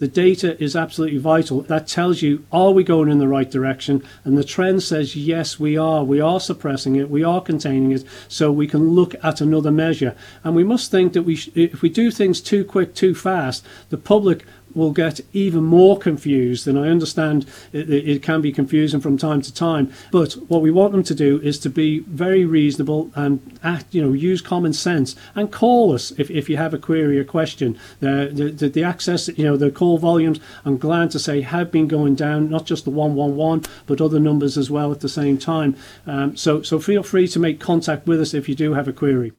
Speaking at one of the recent government media briefings, Laurence Skelly discussed government's decision to allow construction workers, gardeners, and window cleaners to return to work at the end of last week.